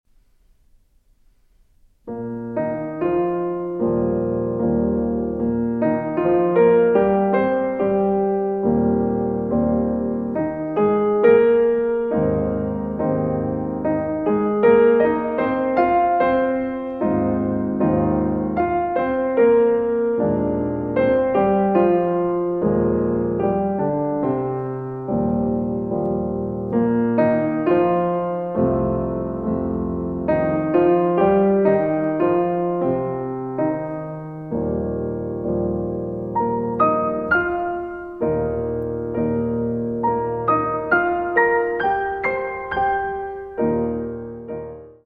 Andantino mosso